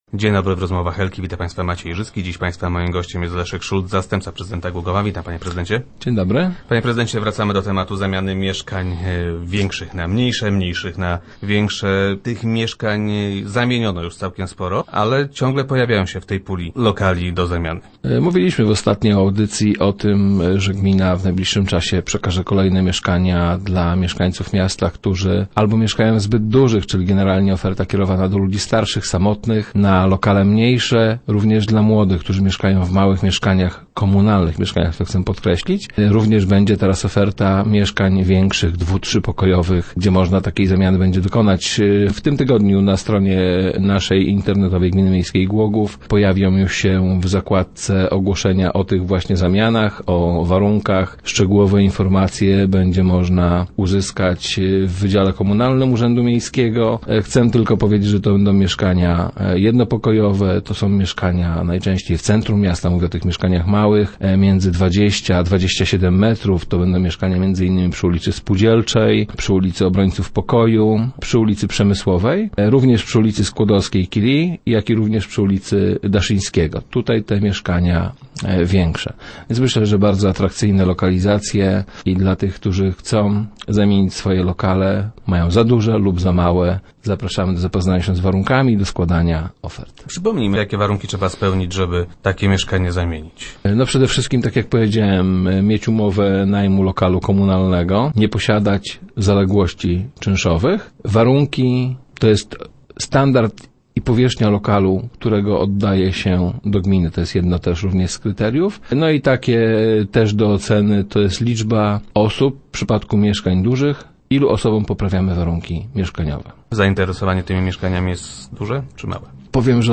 - Pojawiły się już kolejne mieszkania do zamiany - informuje Leszek Szulc, zastępca prezydenta Głogowa, który był gościem Rozmów Elki.